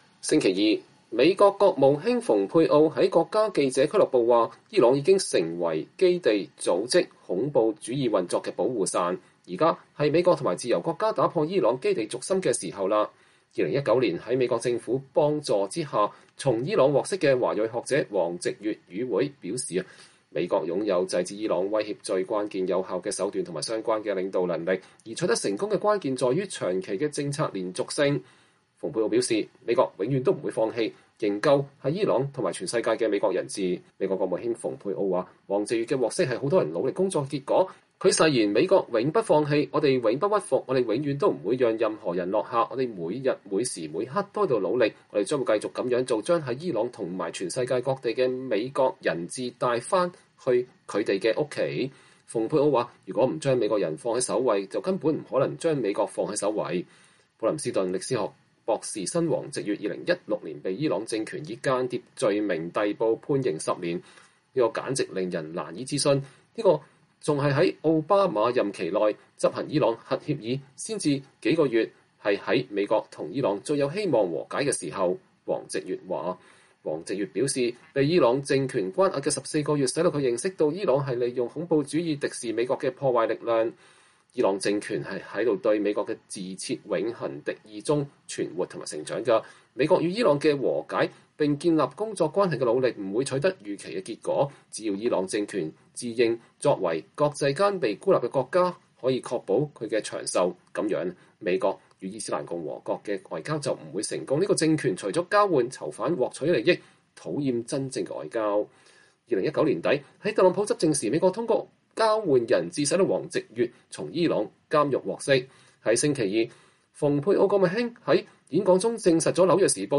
美國國務卿蓬佩奧1月12日在國家記者俱樂部發表演說（路透社）